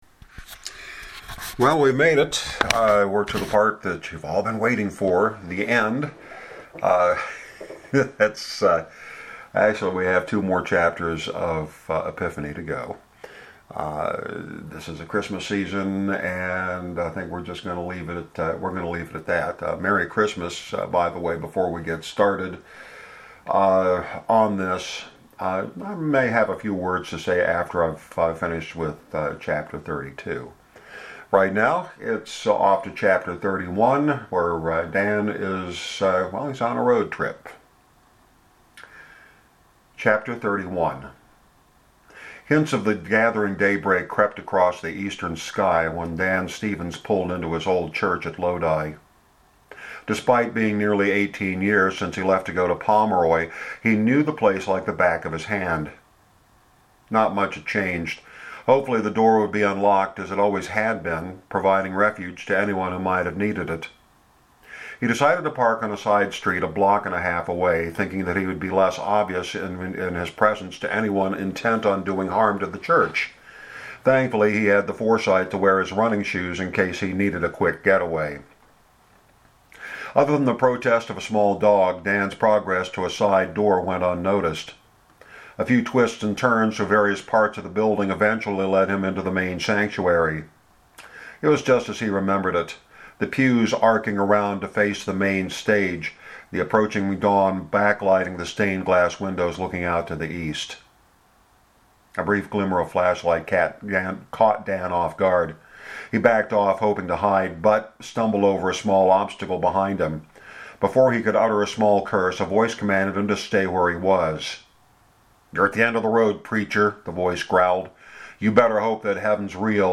Note that I get a little caught up in the emotion toward the end.